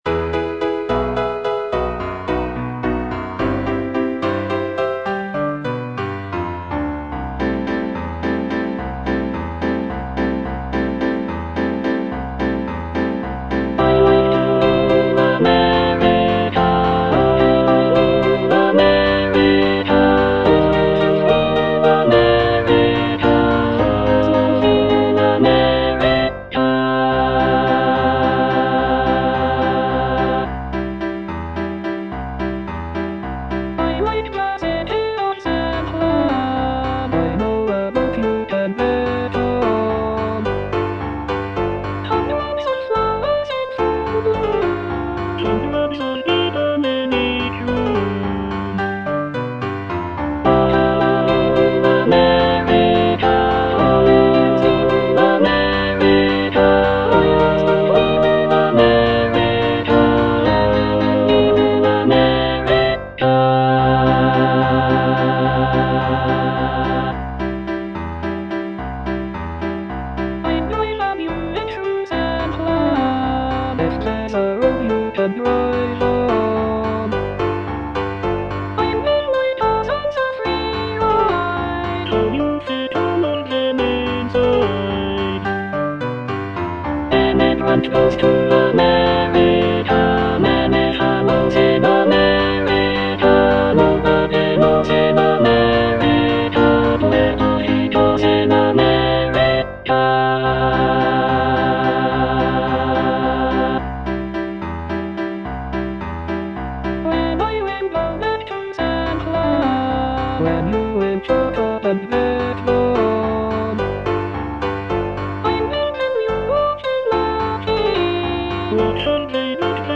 All voices